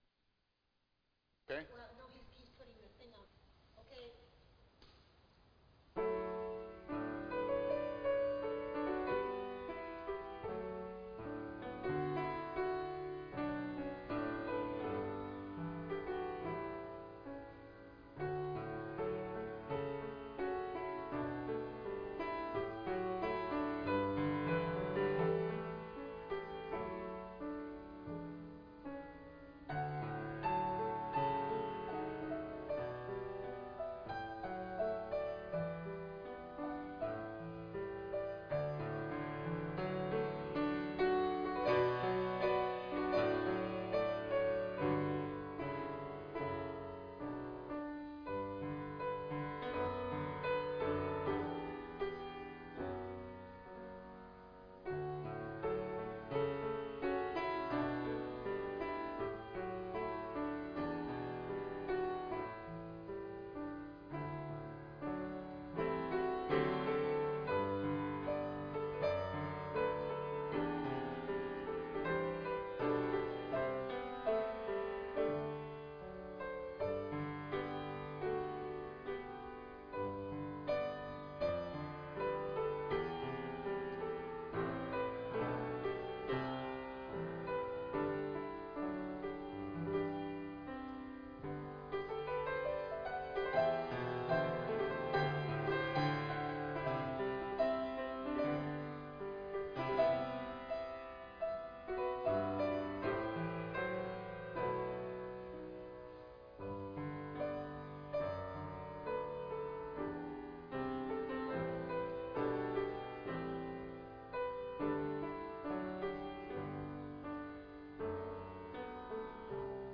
Fellowship Church is pleased to offer this live service at 10AM via Zoom.